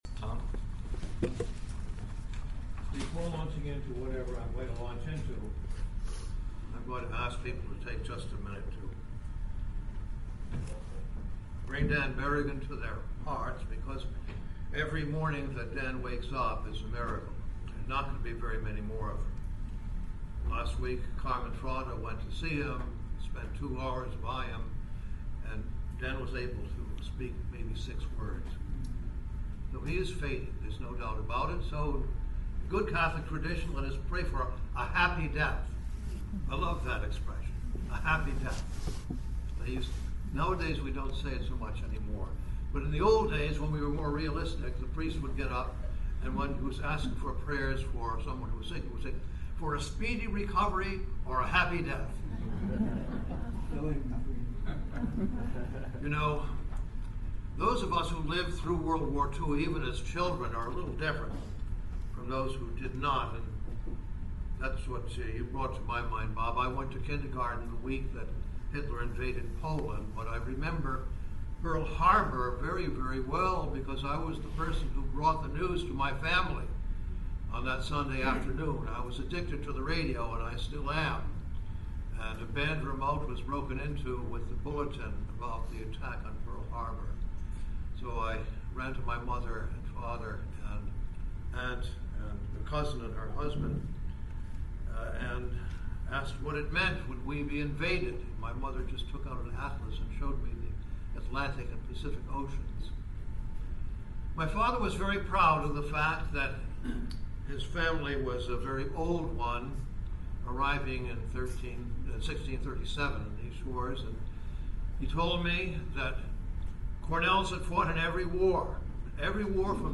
Fifty years ago a handful of peace advocates gathered with the contemplative monk and writer Thomas Merton to discuss the “spiritual roots” that nurtured their calling and shaped their actions. In October of 2014 the CPF co-sponsored an event entitled PURSUING THE SPIRITUAL ROOTS OF PROTEST- 1964-2014 with the Thomas Merton Center at Bellarmine University in Louisville, Kentucky which explored this gathering, which contributed to the formation of the Catholic Peace Fellowship.